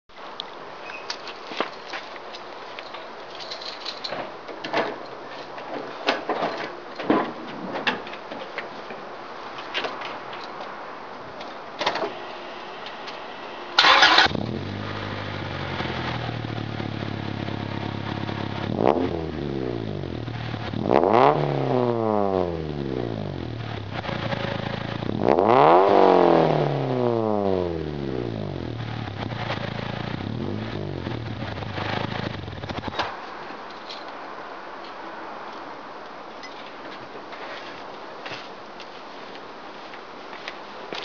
Soundfile Muffler